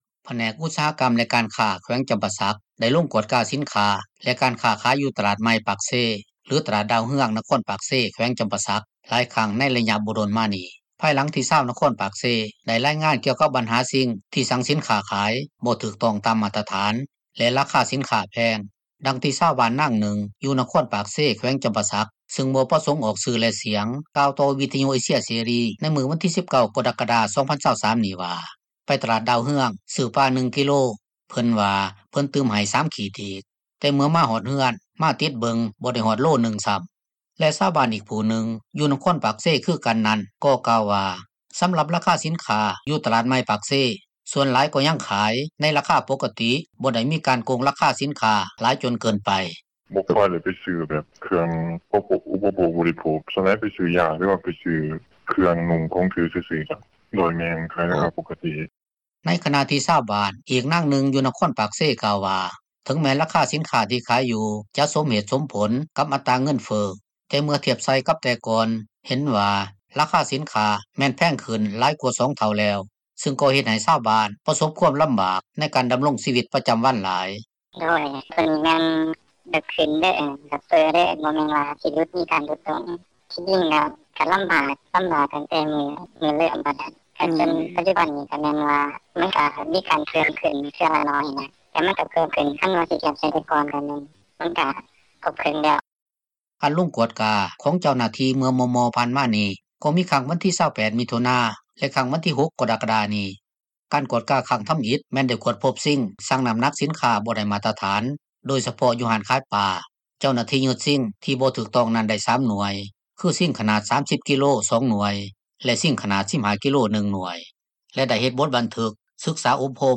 ຜູ້ຄ້າຂາຍບາງເຈົ້າ ໃຊ້ຊິງຊັ່ງສິນຄ້າ ບໍ່ໄດ້ມາຕຖານ ຢູ່ຕລາດດາວເຮືອງ — ຂ່າວລາວ ວິທຍຸເອເຊັຽເສຣີ ພາສາລາວ